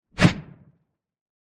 punch_long_whoosh_30.wav